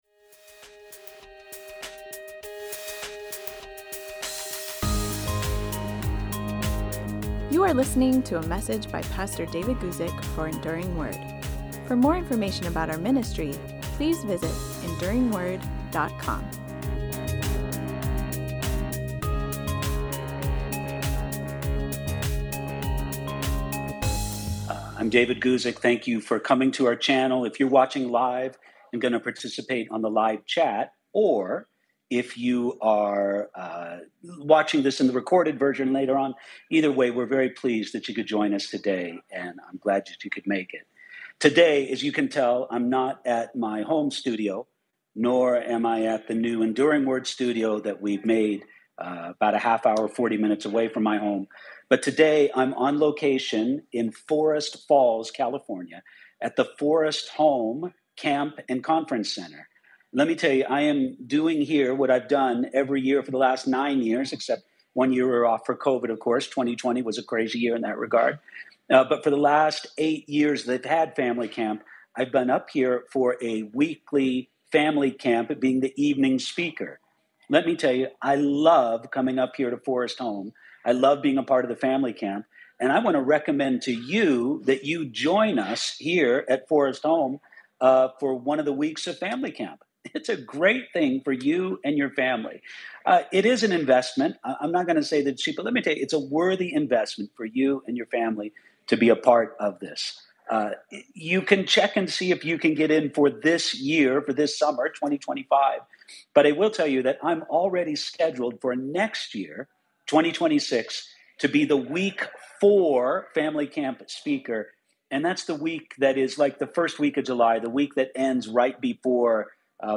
I’m Convicted of Sin, but Can’t Turn Away: Am I Saved? LIVE Q&A for June 26, 2025